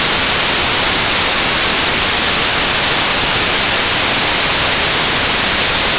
random noise generator output.wav
random_noise_generator_output.wav